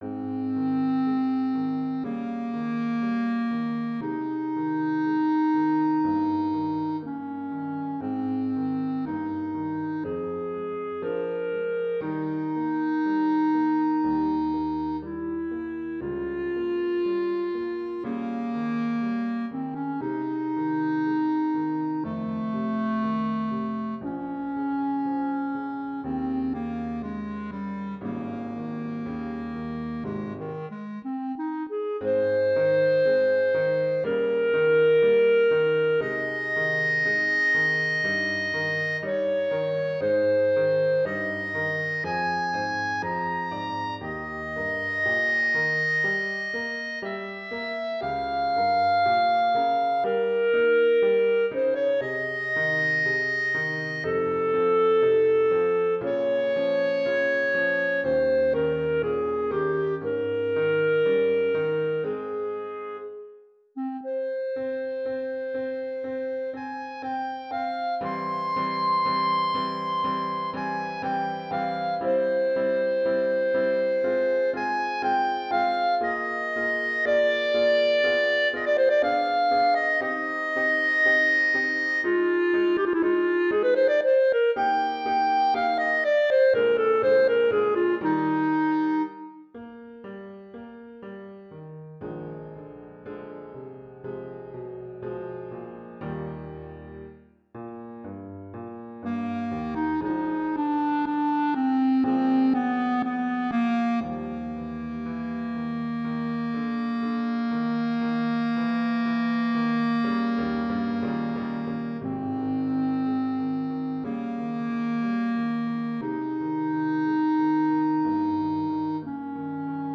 Voicing: Bb Clarinet and Piano